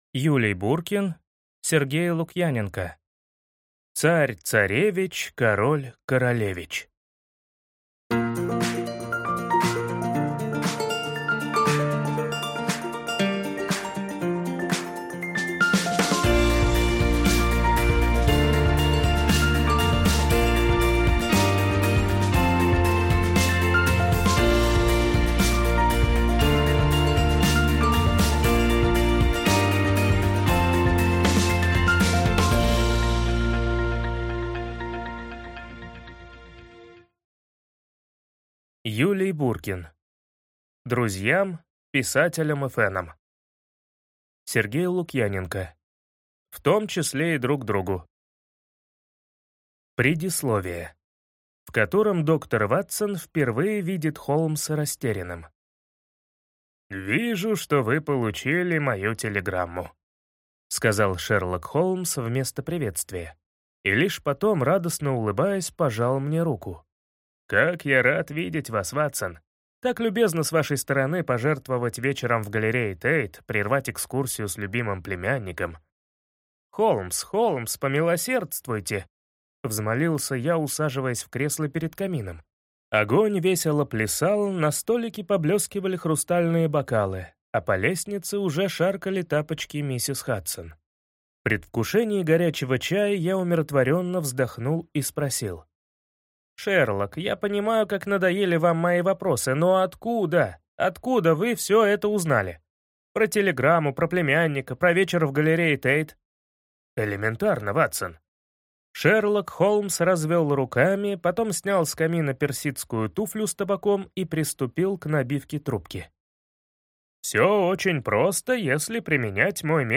Аудиокнига Царь, царевич, король, королевич…